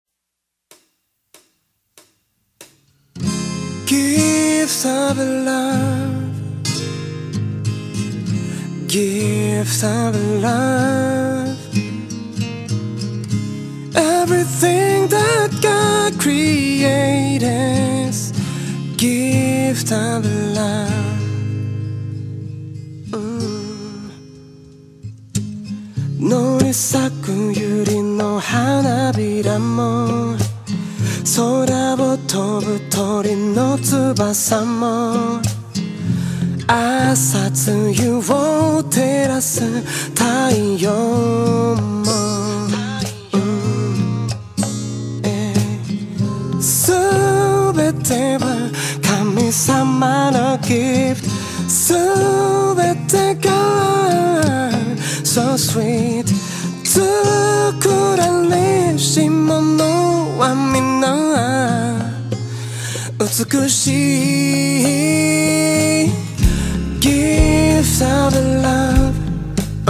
R&Bからジャズまで幅広い音楽的要素を持ち